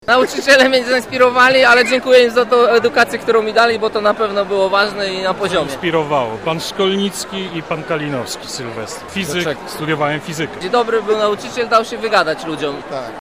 Absolwenci, którzy przybyli na jubileusz, wspominali przede wszystkim nauczycieli. Rozmawiał z nimi nasz reporter.